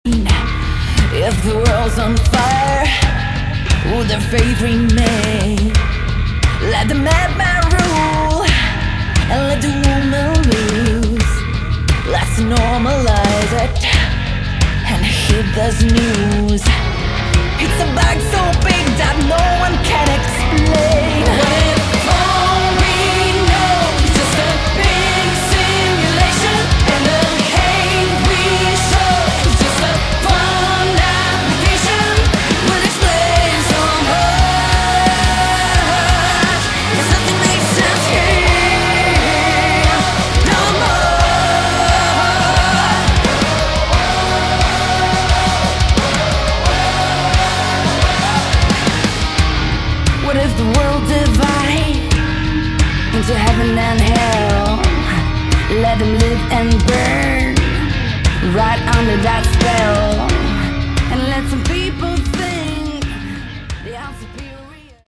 Category: Melodic Metal
vocals
guitars
bass
drums
keyboards, piano